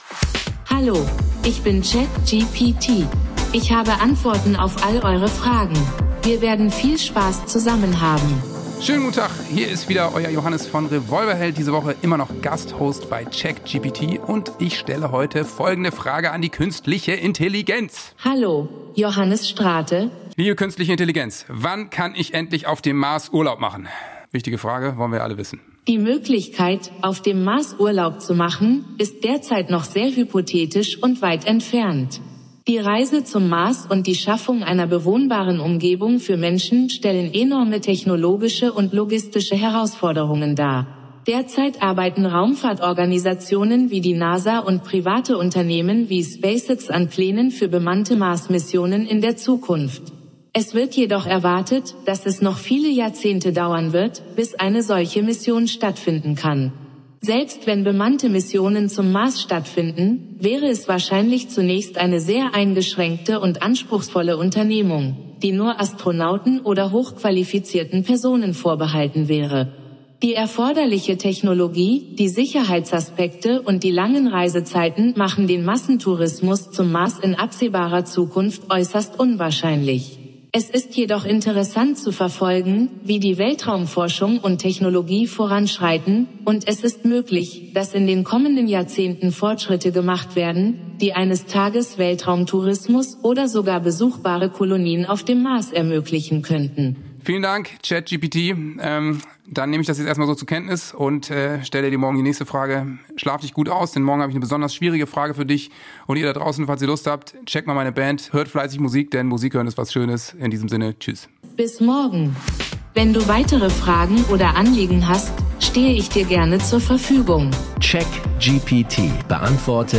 Johannes Strate & KI